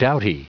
Prononciation du mot doughty en anglais (fichier audio)
Prononciation du mot : doughty